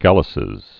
(gălə-sĭz)